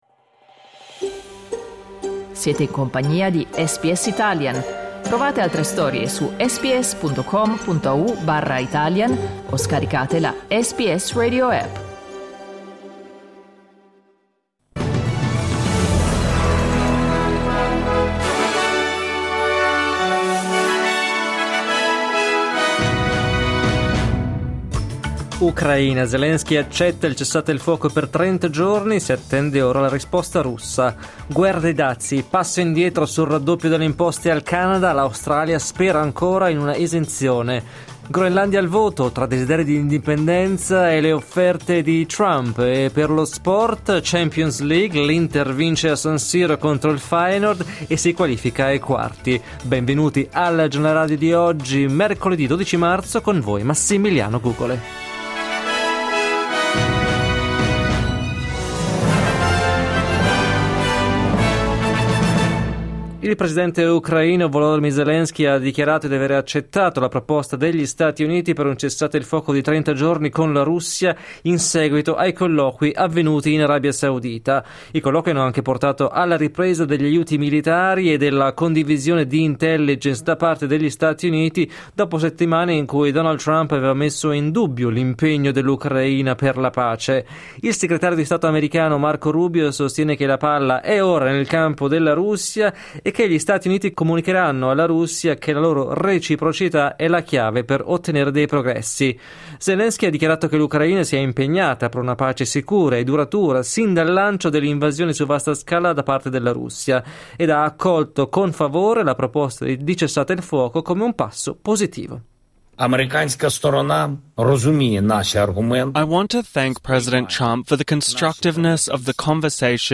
… continue reading 1 Giornale radio mercoledì 12 marzo 2025 10:57 Play Pause 1h ago 10:57 Play Pause 나중에 재생 나중에 재생 리스트 좋아요 좋아요 10:57 Il notiziario di SBS in italiano.